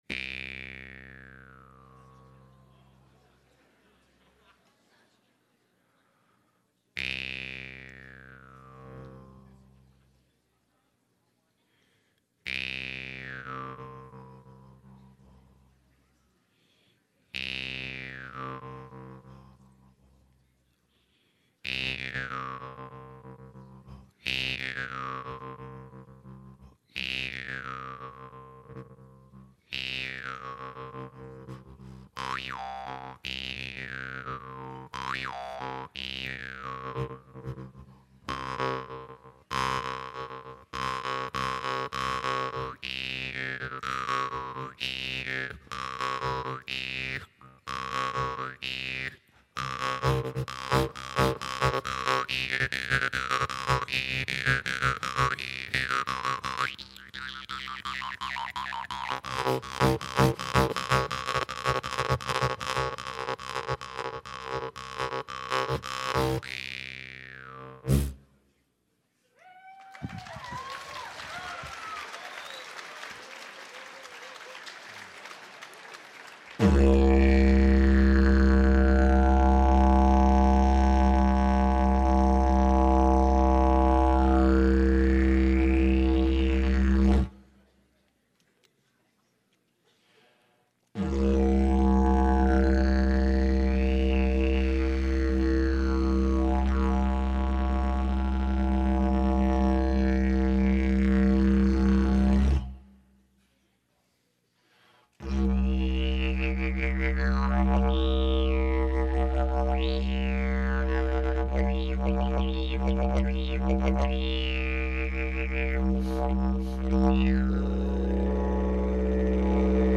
Live at the Caribou Performing Arts Center